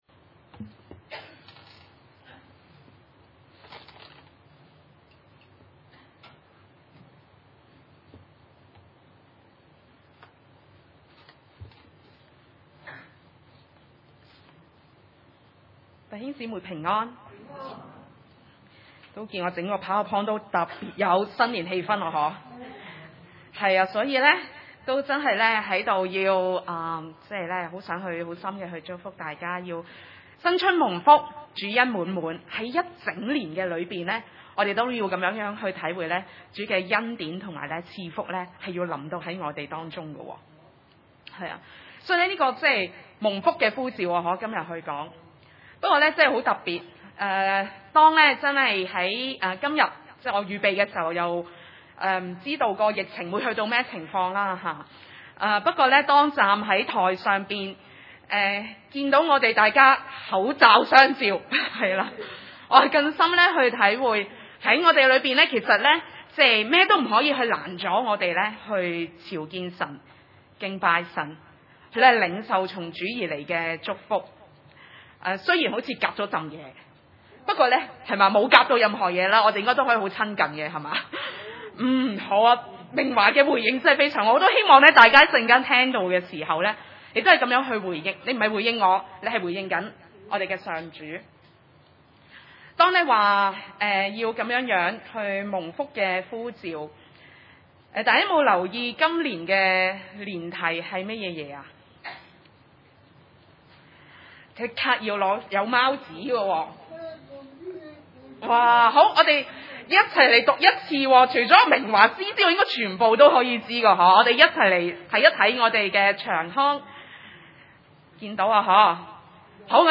創世記 12:1-9 崇拜類別: 主日午堂崇拜 1 耶和華對亞伯蘭說：「你要離開本地、本族、父家，往我所要指示你的地去。